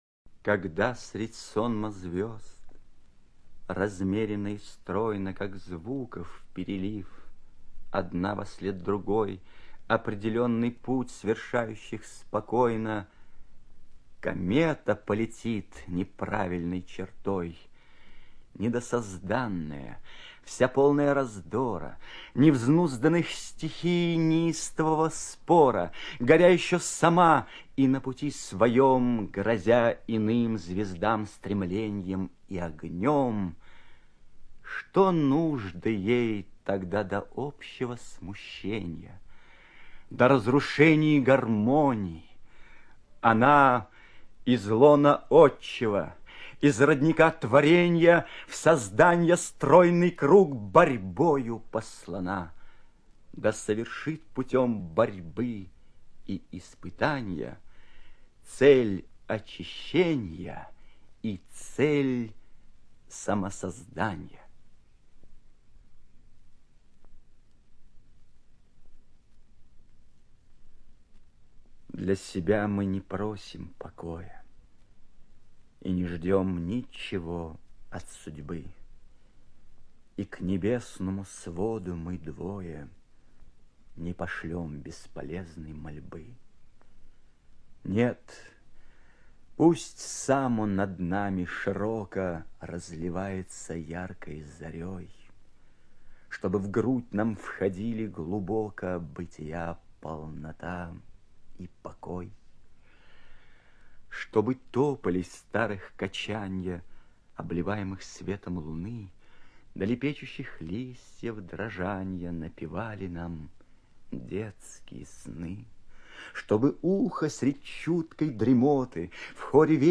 ЧитаетМарцевич Э.
ЖанрПоэзия